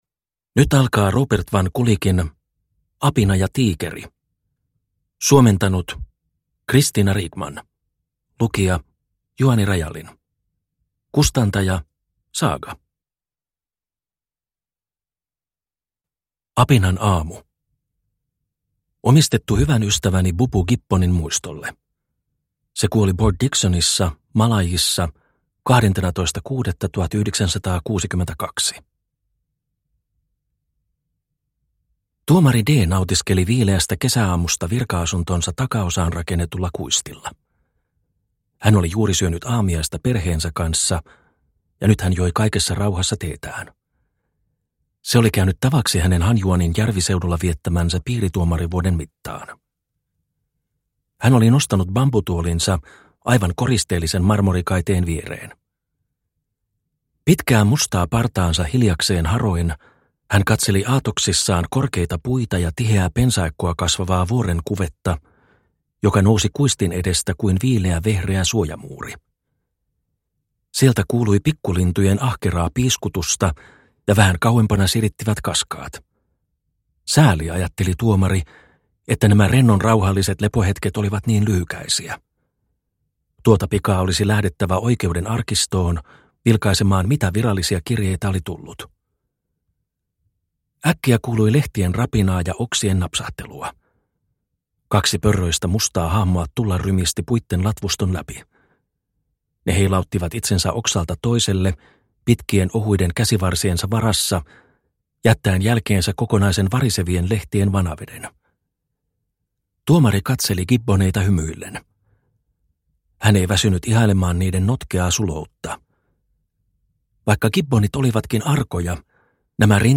Apina ja tiikeri (ljudbok) av Robert van Gulik